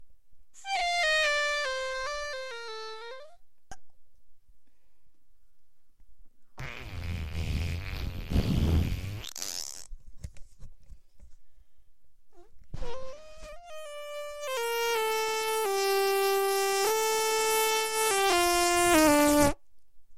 Autotuned fart sound
Category 🤣 Funny